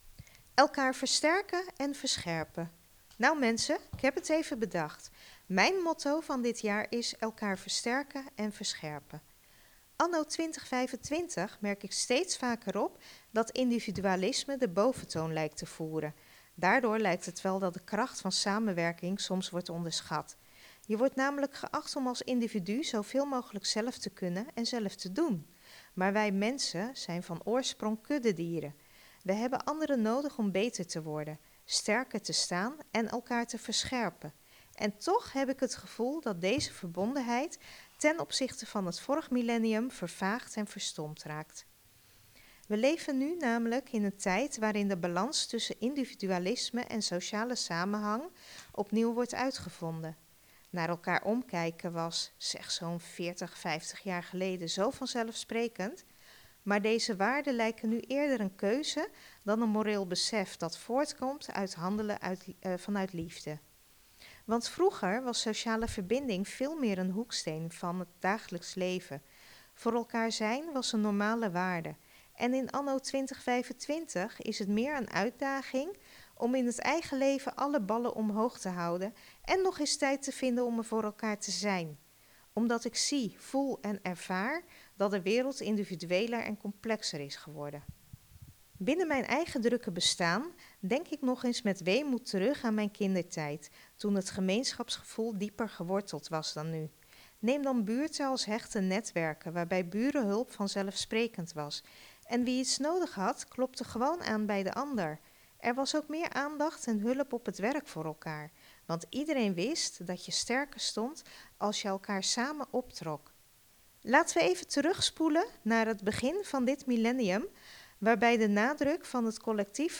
Column